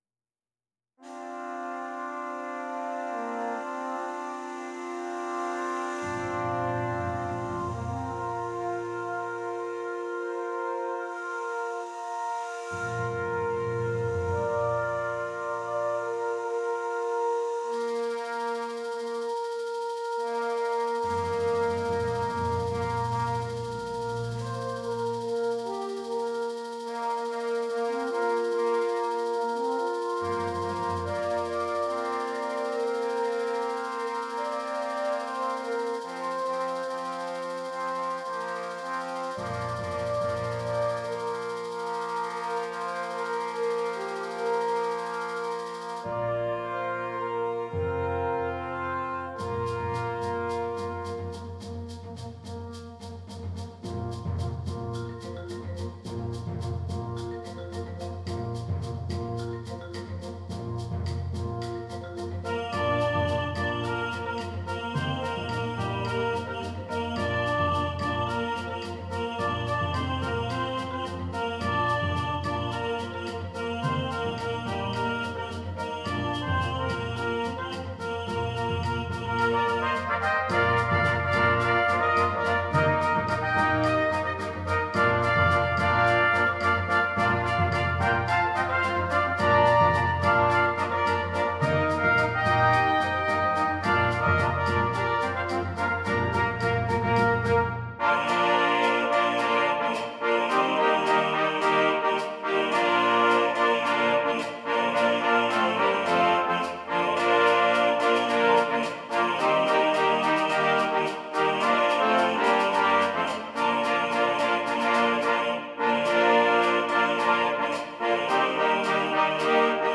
6 Trumpets
Traditional South African Song